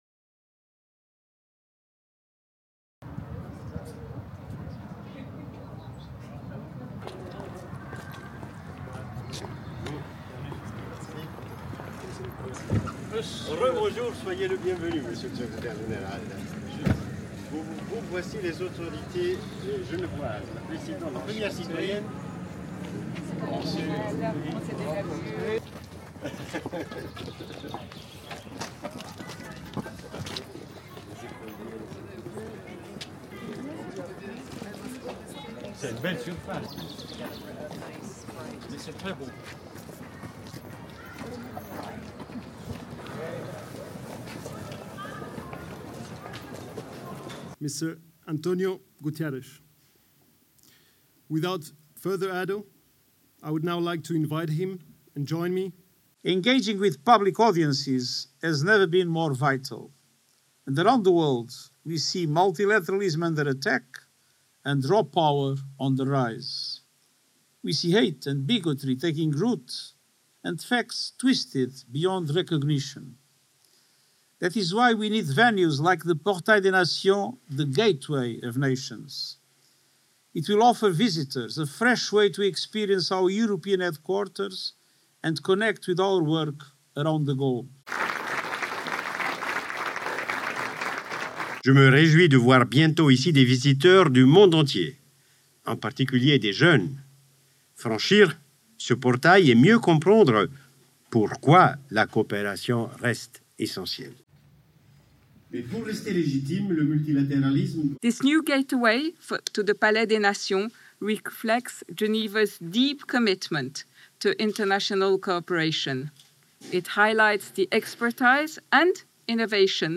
LANGUE : ANGLAIS / FRANÇAIS / NATS
9. Intérieur moyen large, maître de cérémonie introduit le Secrétaire général de l'ONU António Guterres, son en direct.
11. Intérieur, panoramique à gauche, public applaudissant.
19. Plan panoramique, participants applaudissant